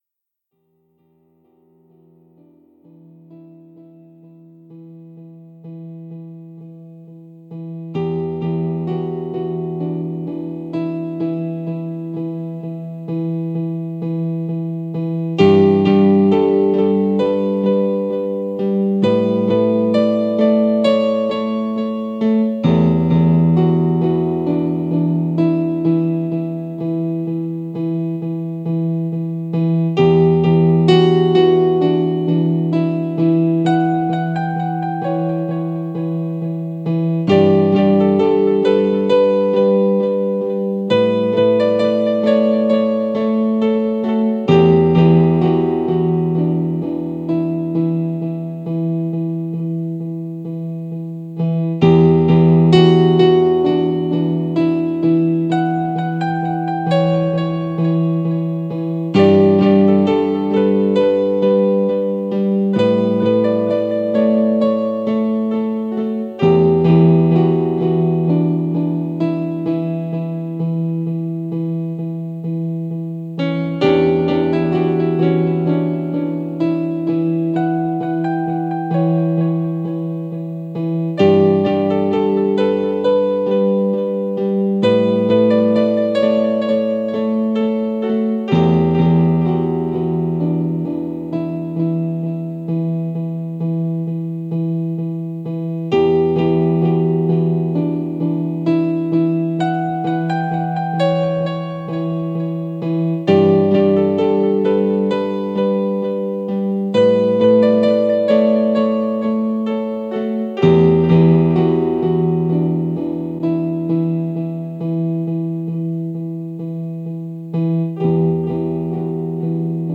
03-UoUO_PIANO.mp3